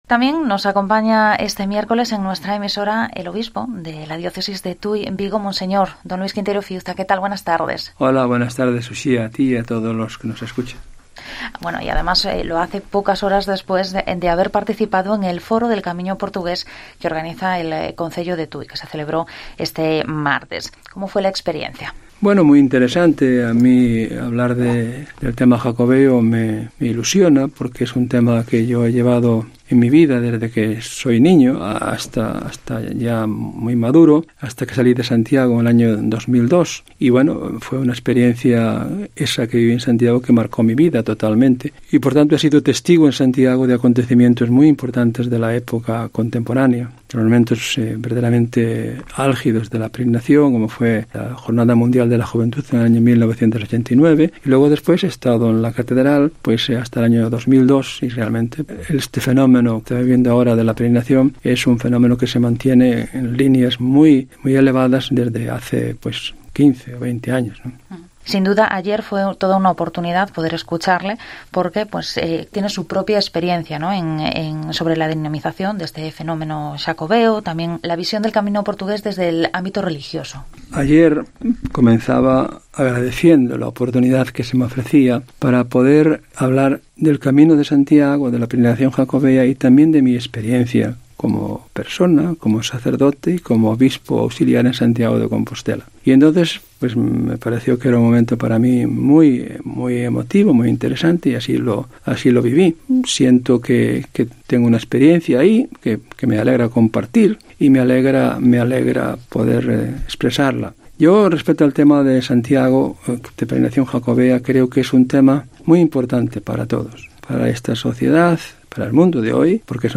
Entrevista a Monseñor D. Luis Quinteiro Fiuza, obispo de Tui-Vigo